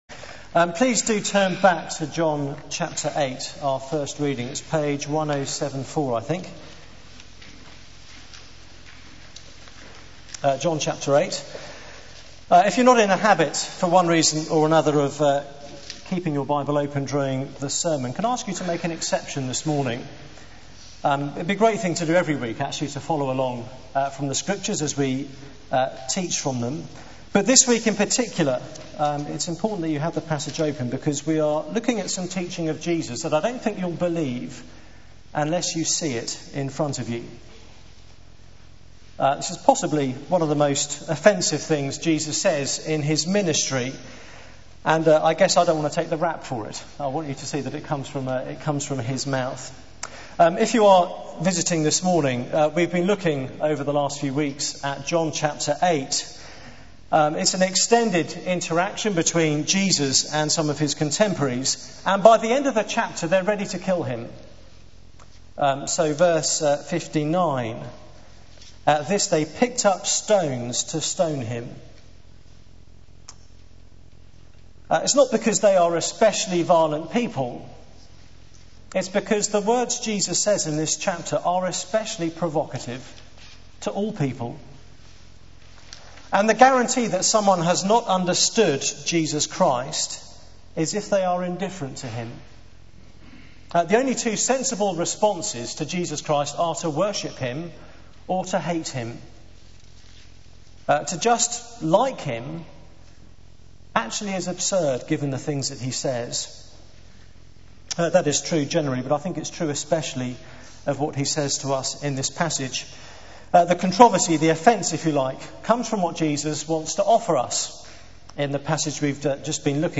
Media for 9:15am Service on Sun 10th May 2009 09:15 Speaker: Passage: John 8:31-47 Series: Claims to be Stoned For Theme: The One Who Sets Us Free Sermon Search the media library There are recordings here going back several years.